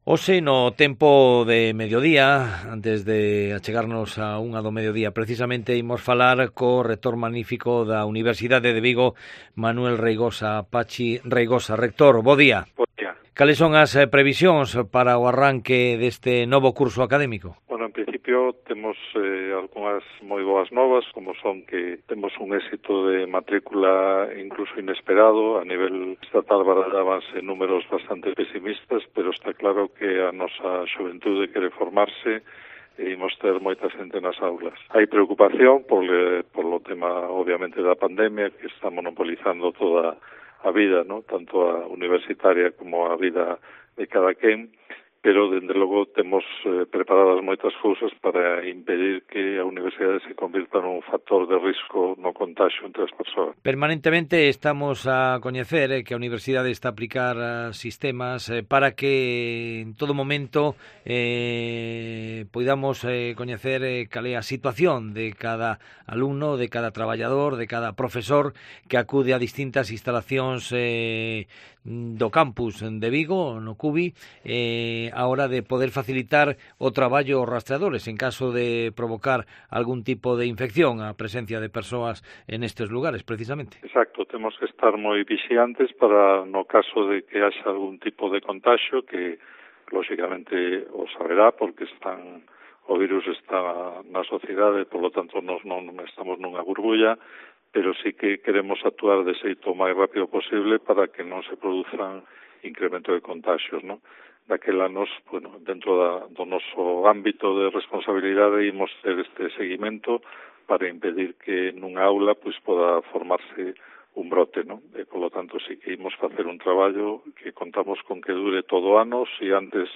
El rector de la UVigo, Pachi Reigosa, nos habla del inicio del curso